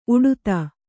♪ uḍuta